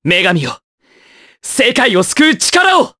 Kasel-Vox_Skill6_jp.wav